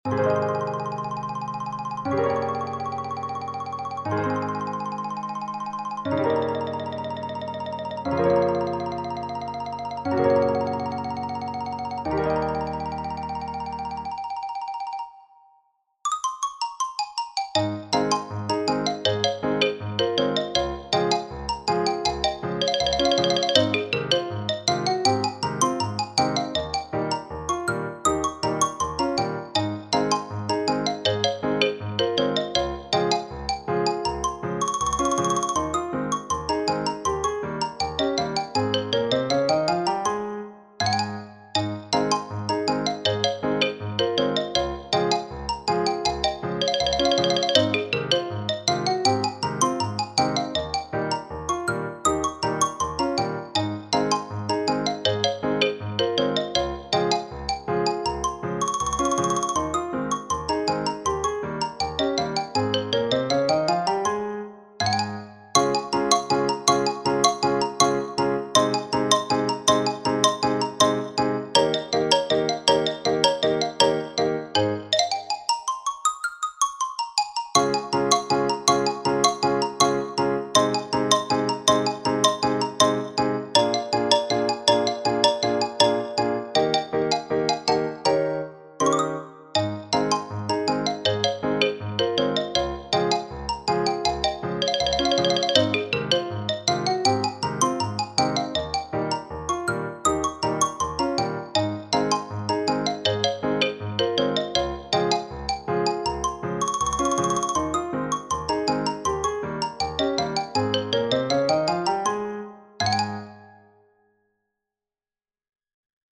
solo with piano accompaniment
xylophone solo